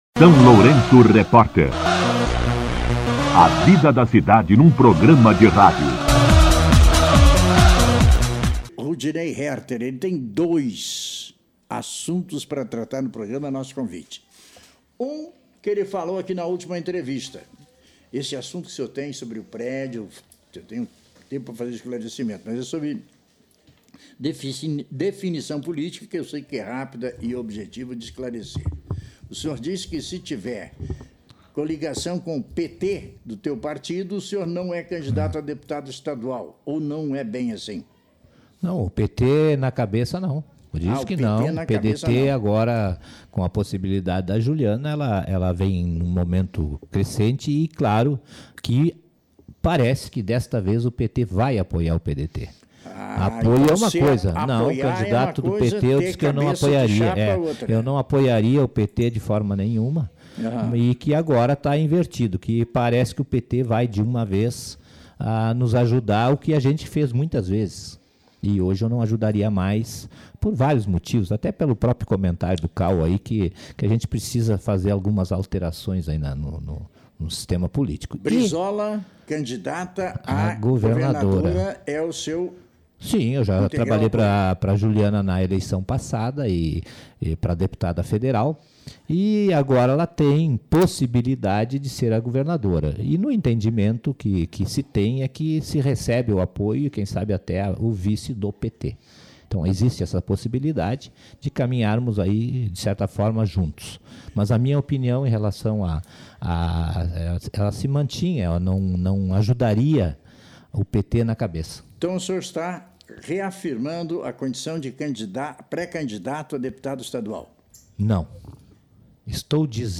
Entrevista com ex-prefeito Rudinei Härter
entrevista-15.04-rudinei-harter.mp3.mp3